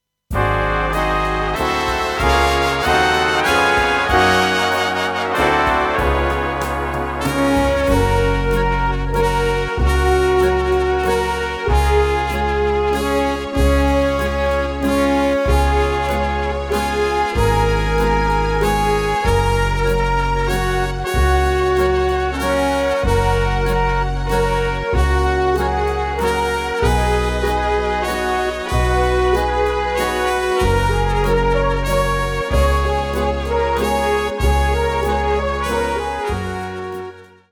Notenliteratur mit 10 Melodien für Alphorn in Fis
Erhältlich mit Solo / Playback CD